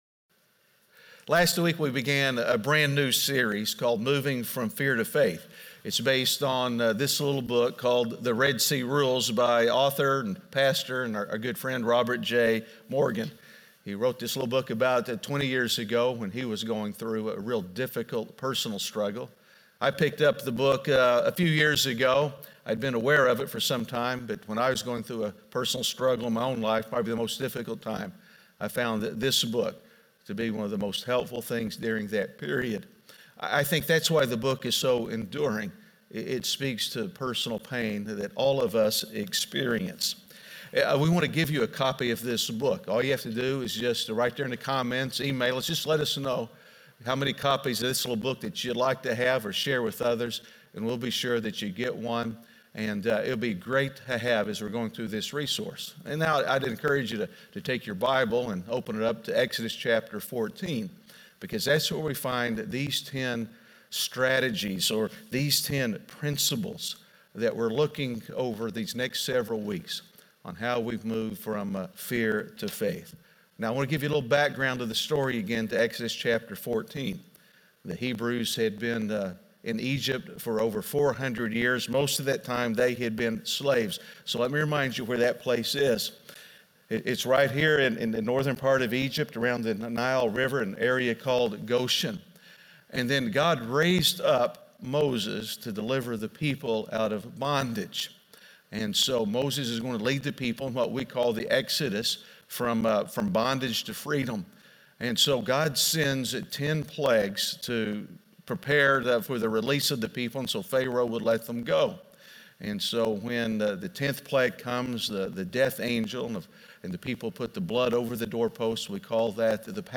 Moving From Fear To Faith (Week 2) - Sermon - MP3 Audio Only.mp3